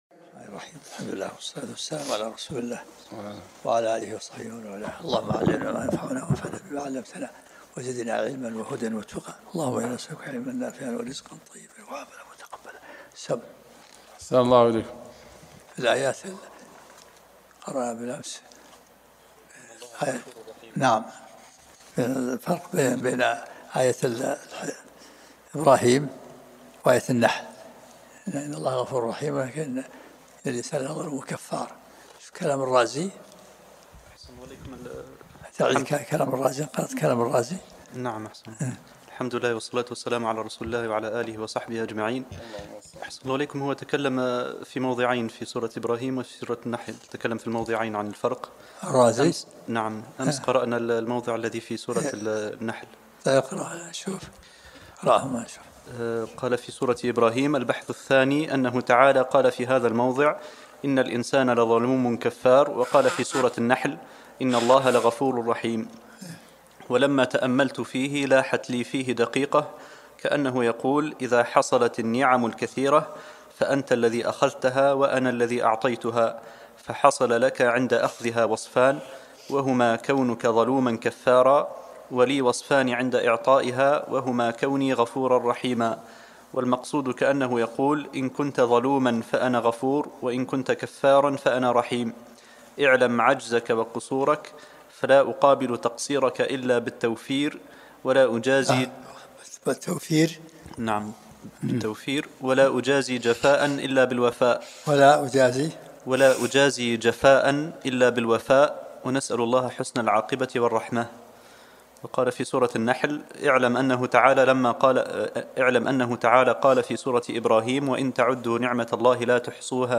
الدرس الثالث من سورة النحل